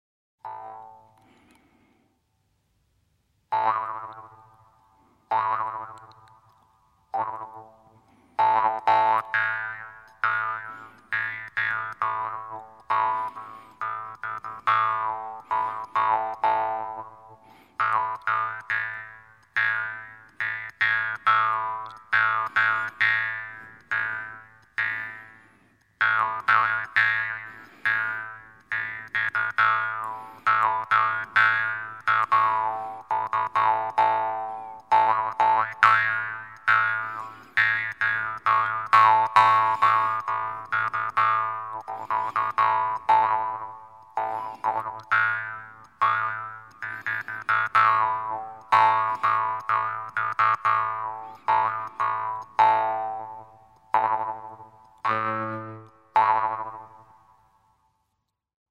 冬不拉是哈萨克族最重要、也最流行的弹拨乐器， 灵跳的旋律和独特的节奏，一直是中亚乐器的代表。